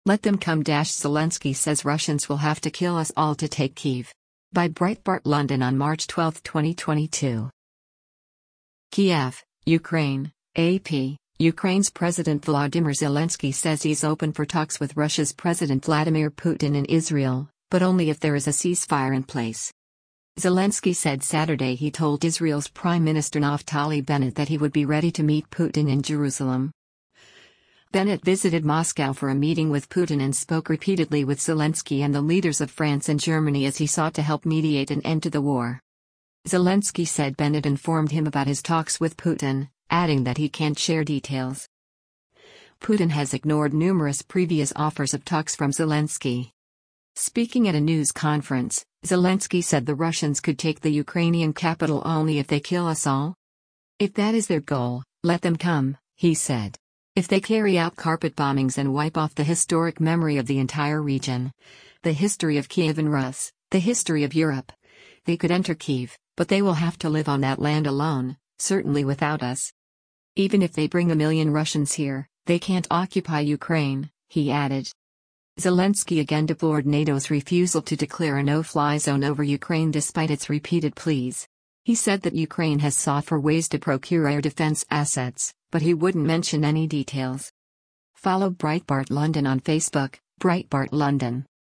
Speaking at a news conference, Zelensky said the Russians could take the Ukrainian capital “only if they kill us all.”